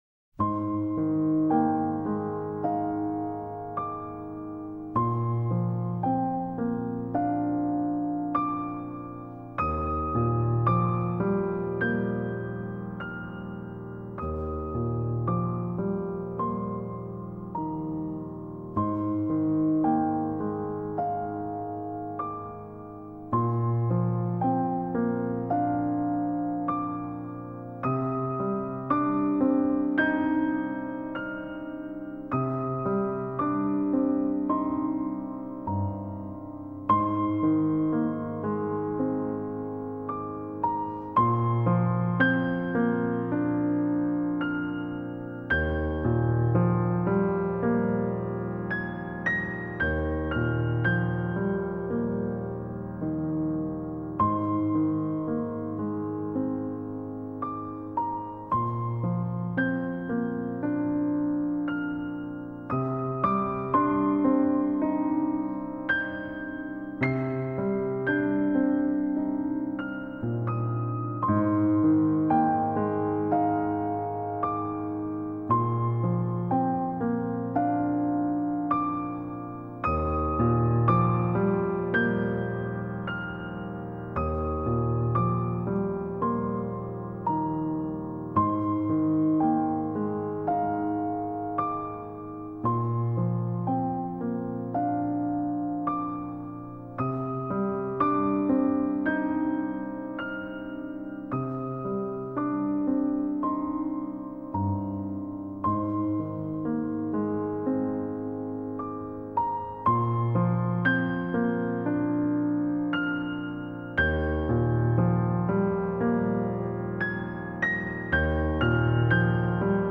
applause.mp3